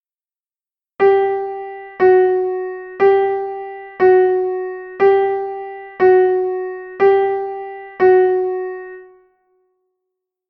Chámase bemol. Fai descender 1/2 ton o son da nota.